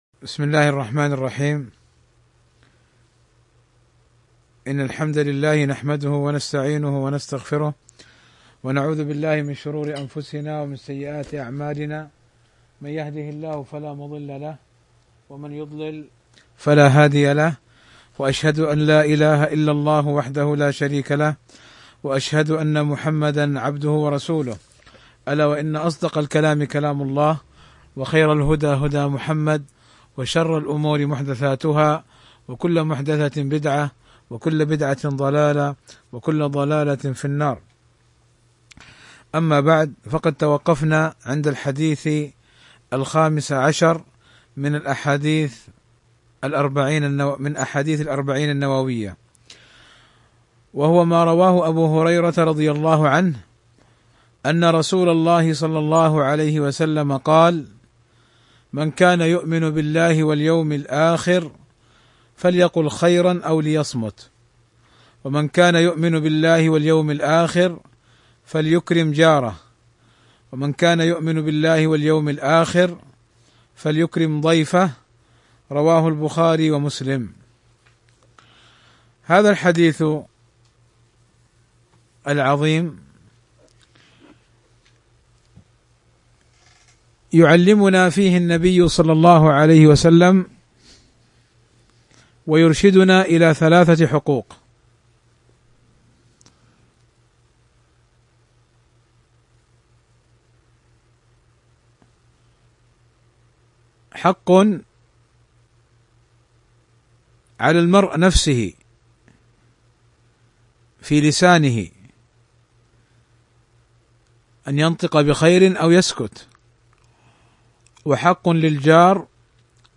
شرح الأربعون النووية الدرس 15